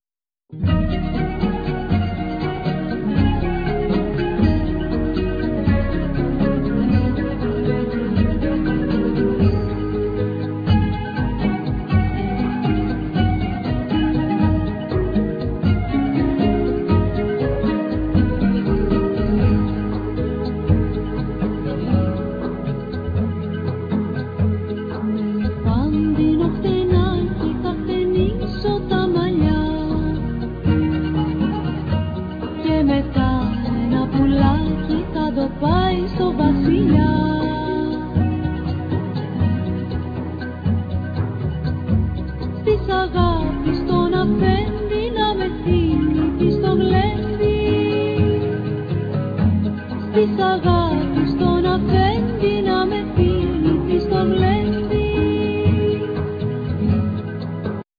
Nylon string guitar,Mandokino,Bass
Vocals
Lyra
Piano,Keyboards
Drums,Percussions